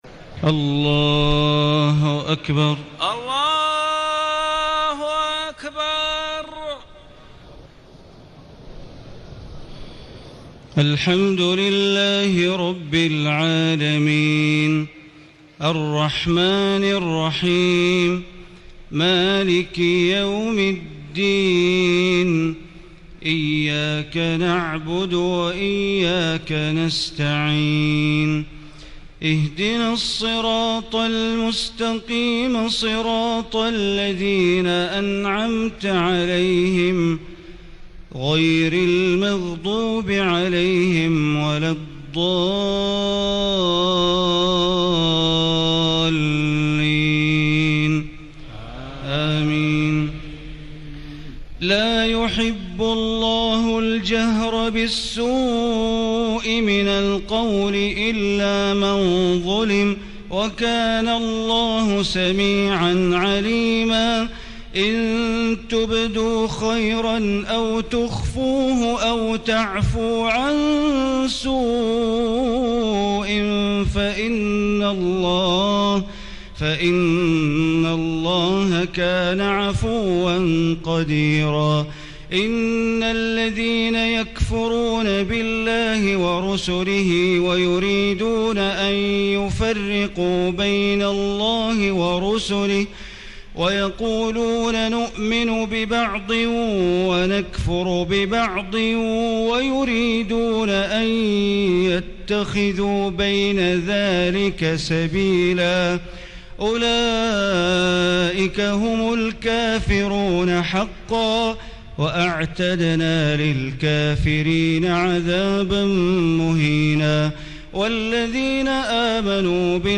تهجد ليلة 26 رمضان 1436هـ من سورتي النساء (148-176) و المائدة (1-40) Tahajjud 26 st night Ramadan 1436H from Surah An-Nisaa and AlMa'idah > تراويح الحرم المكي عام 1436 🕋 > التراويح - تلاوات الحرمين